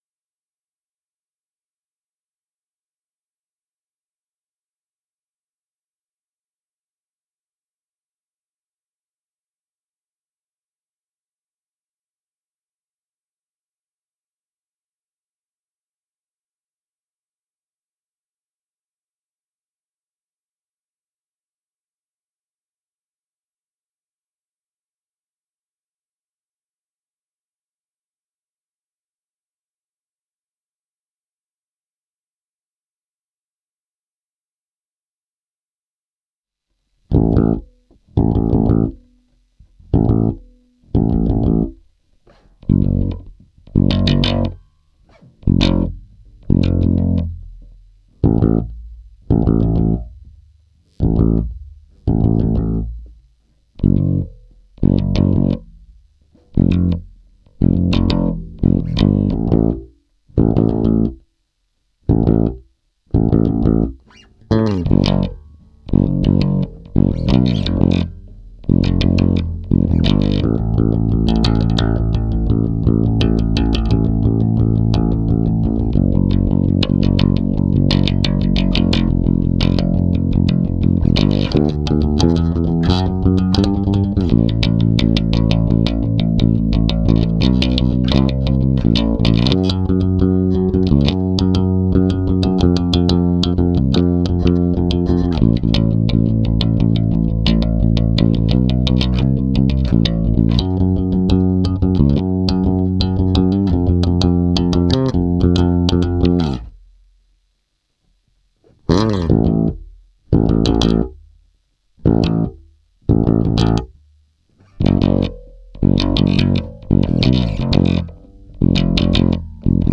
(basse uniquement)
Cover / Instrumentale